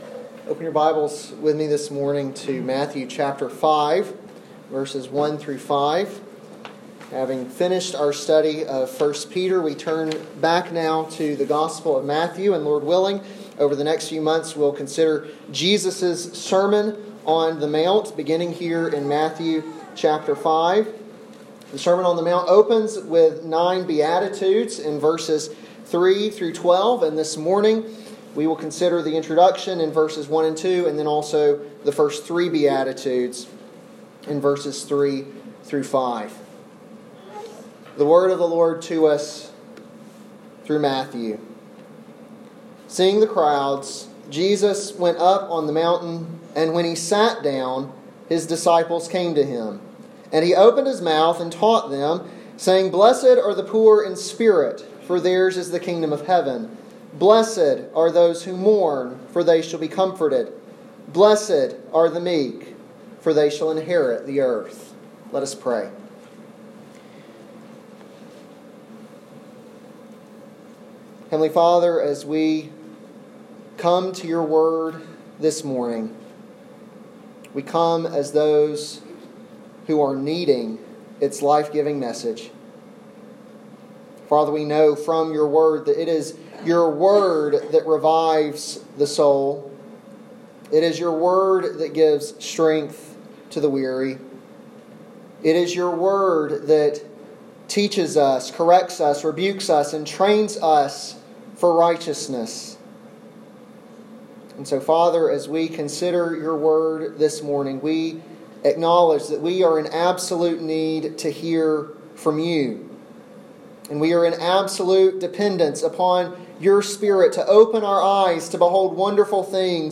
an expository sermon on Matthew 5:1-5